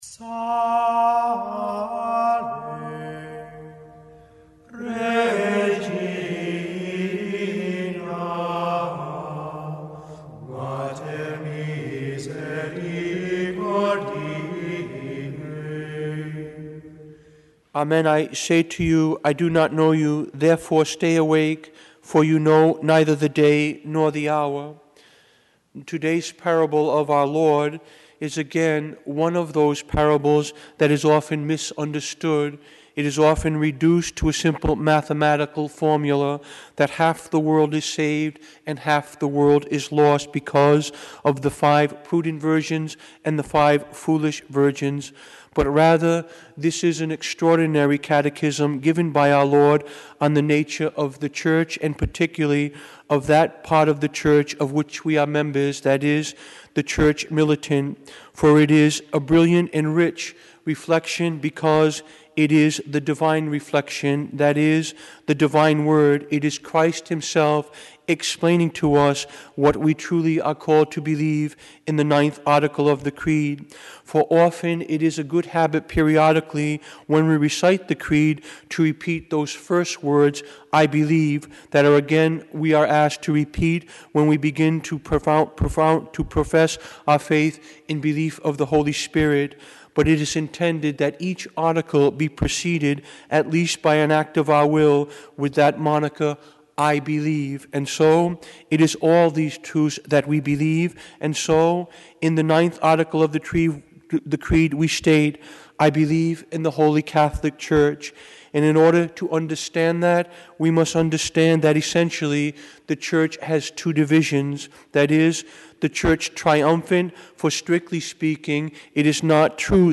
Homily
Mass: 32nd Sunday in Ordinary Time - Sunday - Form: OF Readings: 1st: wis 6:12-16 Resp: psa 63:2, 3-4, 5-6, 7-8 2nd: 1th 4:13-17 Gsp: mat 25:1-13 Audio (MP3) +++